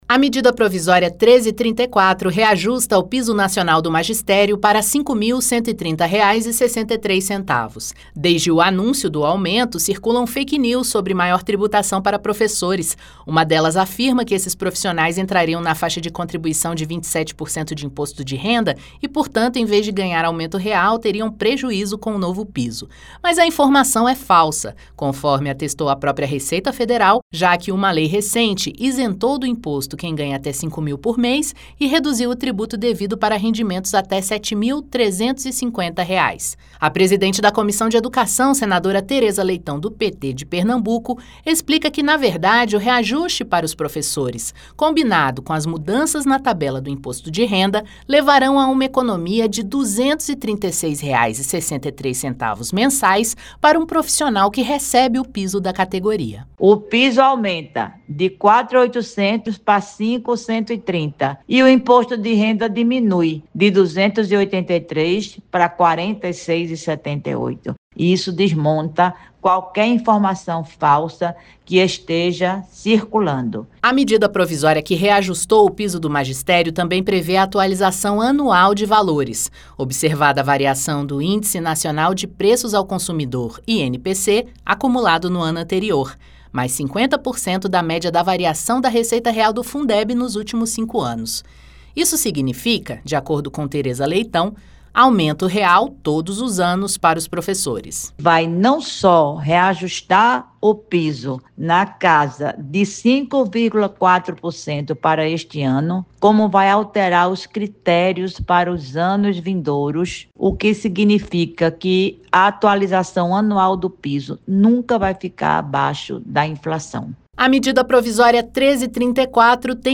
Mas, desde o anúncio dessa medida medida provisória, passaram a circular notícias falsas de que haverá aumento na tributação sobre professores. A presidente da Comissão de Educação e Cultura do Senado, Teresa Leitão (PT-CE), explica por que essa fake news não se sustenta.